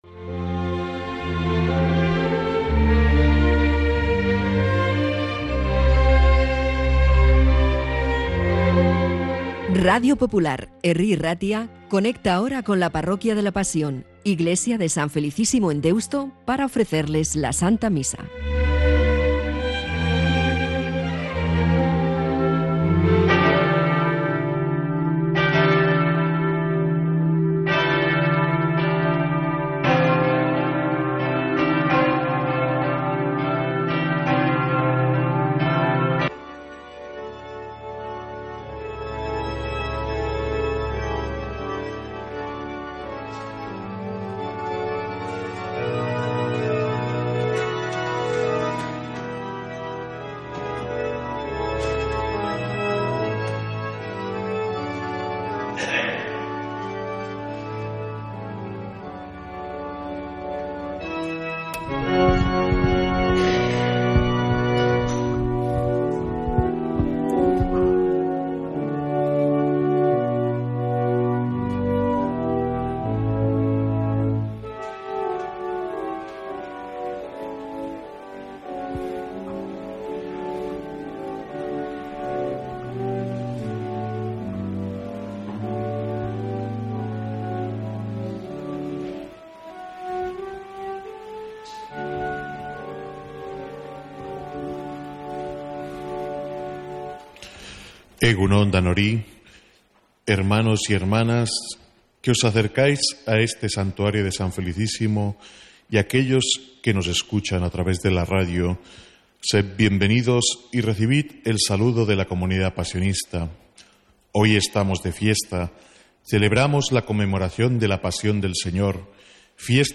Santa Misa desde San Felicísimo en Deusto, domingo 2 de marzo